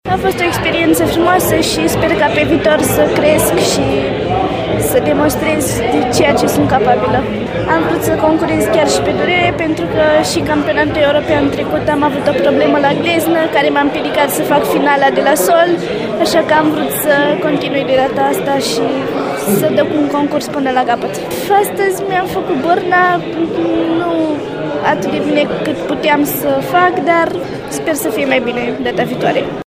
Le puteți asculta alături pe cele două foarte tinere gimnaste ale noastre cu impresii după competiție